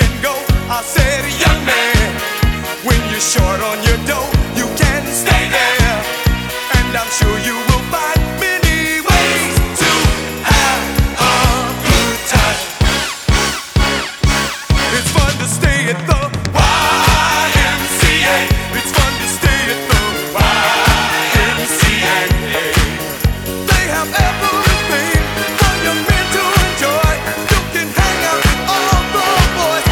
• Disco